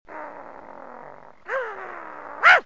Dog Angry Efeito Sonoro: Soundboard Botão
Dog Angry Botão de Som